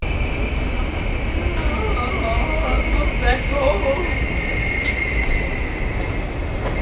Source: Voice & brakes, one octave lower; Spatialization: CW rotation
Voices.aif